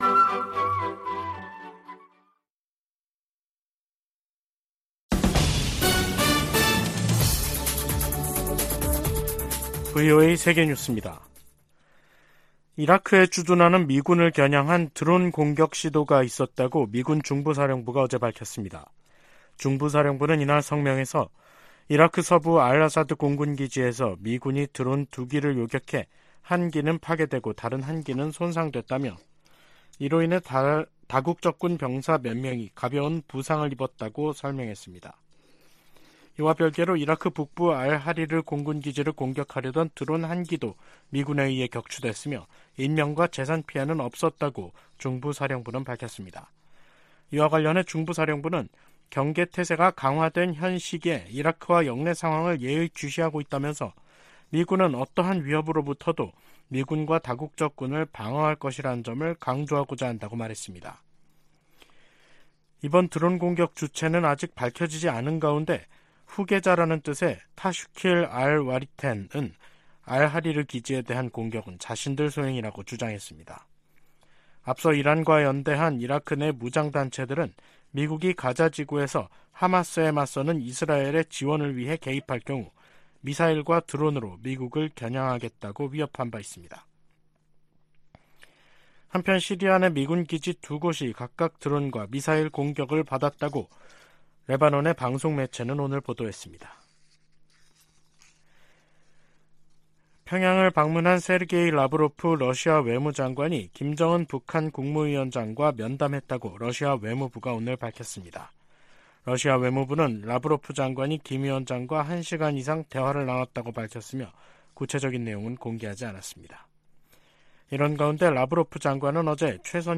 VOA 한국어 간판 뉴스 프로그램 '뉴스 투데이', 2023년 10월 19일 2부 방송입니다. 북한을 방문한 세르게이 라브로프 러시아 외무장관은 양국 관계가 질적으로 새롭고 전략적인 수준에 이르렀다고 말했습니다. 미 상원의원들은 북-러 군사 협력이 러시아의 우크라이나 침략 전쟁을 장기화하고, 북한의 탄도미사일 프로그램을 강화할 수 있다고 우려했습니다. 북한이 암호화폐 해킹을 통해 미사일 프로그램 진전 자금을 조달하고 있다고 백악관 고위 관리가 밝혔습니다.